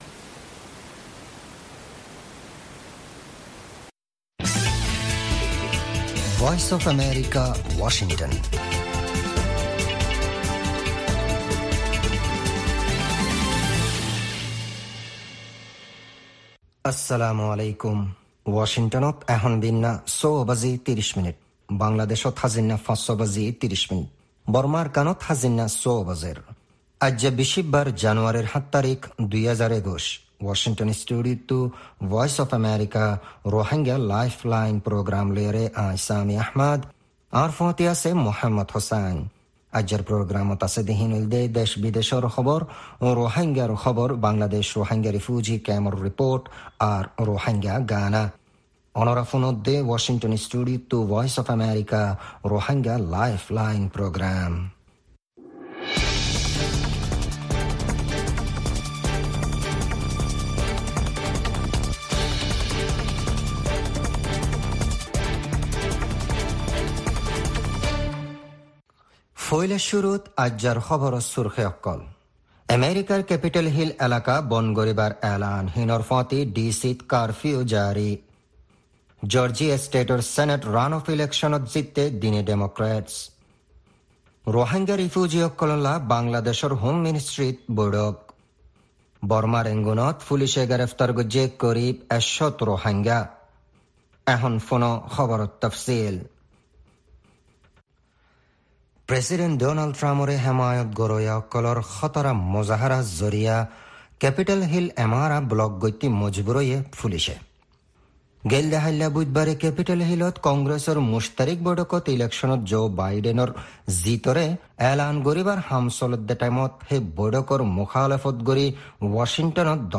News Headlines